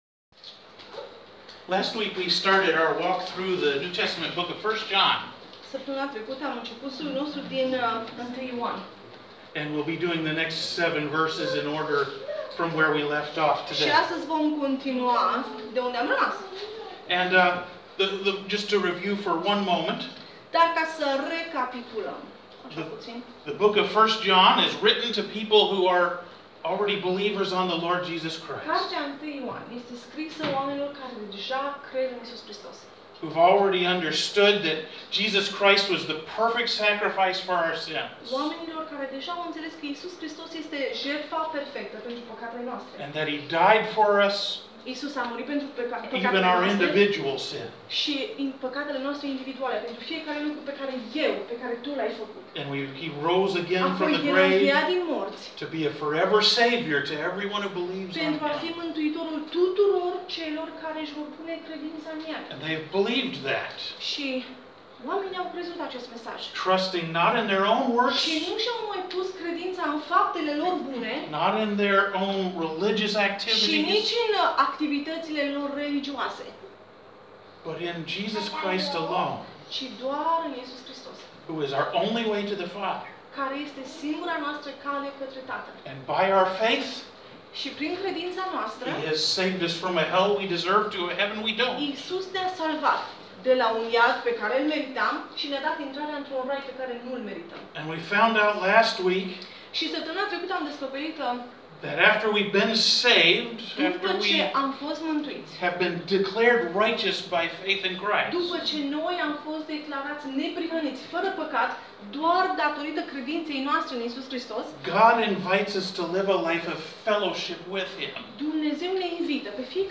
12 mai – Studiu Biblic – sermon audio